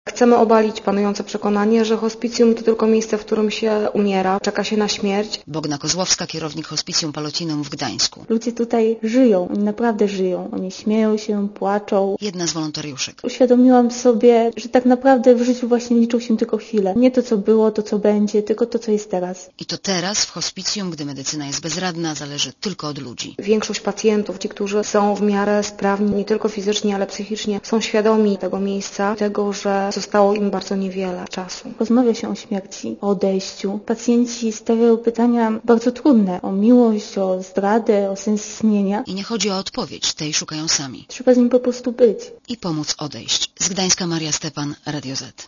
Relacja reportera Radia ZET W Polsce jest około 150 hospicjów.